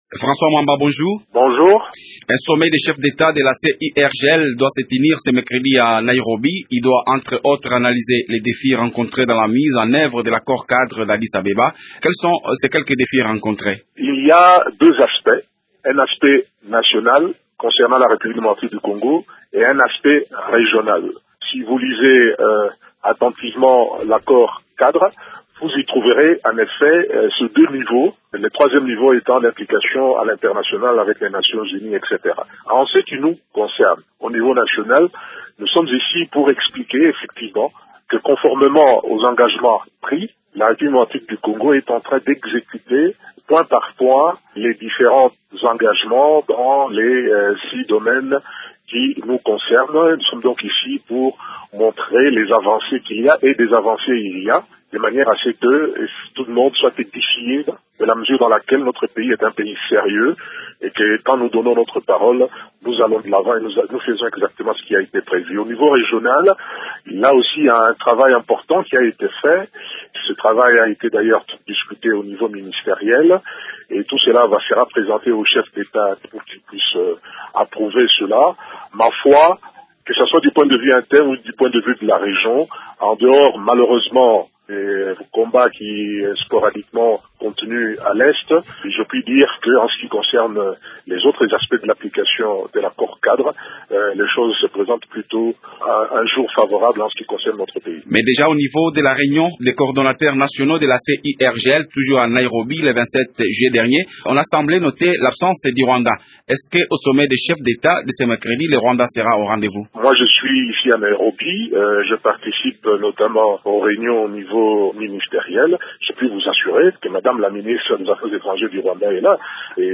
Il s’exprime en marge du sommet extraordinaire des chefs d’État de la Conférence internationale pour la région des Grands Lacs qui s’ouvre mercredi 31 juillet à Naïrobi au Kenya.